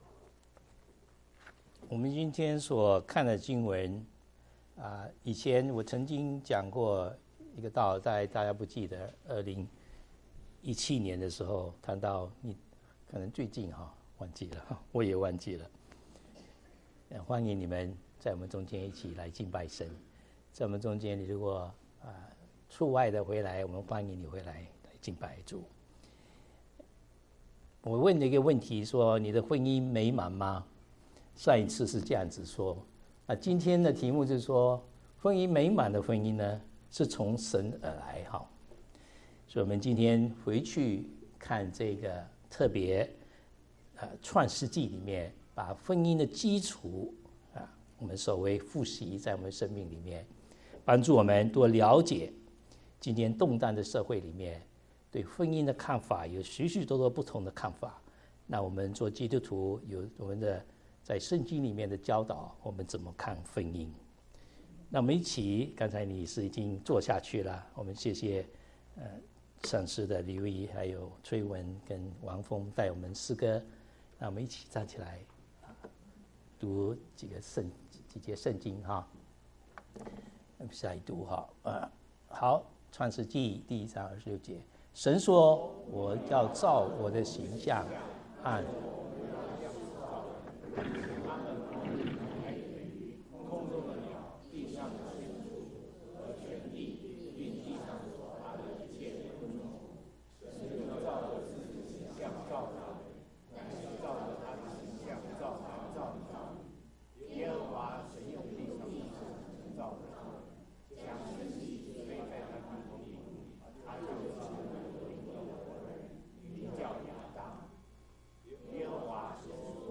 講員